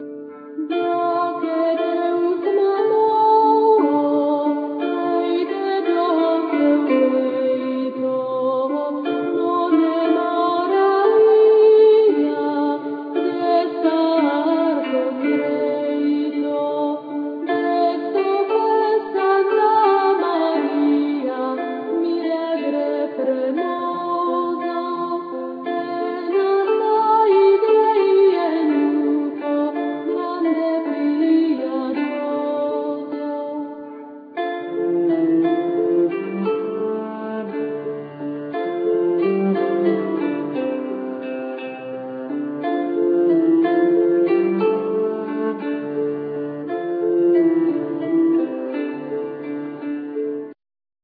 Flute,Percussions,Gittern,Vocals,Shawm,Harp
Gittern,Saz,Tarabuka,Vocals,Hurdy gurdy
Shawm,Bagpipes,Harp
Santur,Fiddle,Davul,Percussions